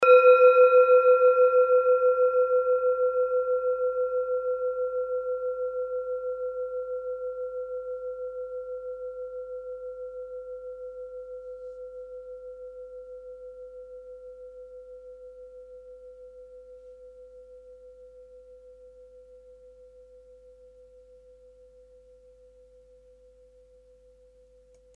Kleine Klangschale Nr.5
Diese Klangschale ist eine Handarbeit aus Bengalen. Sie ist neu und ist gezielt nach altem 7-Metalle-Rezept in Handarbeit gezogen und gehämmert worden.
(Ermittelt mit dem Minifilzklöppel)
Sonnenton:
Die Frequenz des Sonnentons liegt bei 126,2 Hz und dessen tieferen und höheren Oktaven. In unserer Tonleiter ist das nahe beim "H".
kleine-klangschale-5.mp3